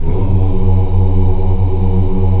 Index of /90_sSampleCDs/Roland LCDP11 Africa VOL-1/VOX_African Oos/VOX_African Oos